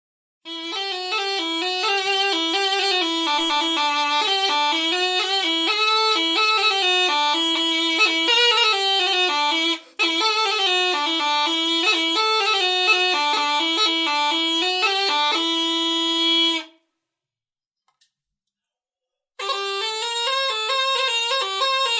Соло на жалейке